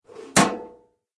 mailbox_close_1.ogg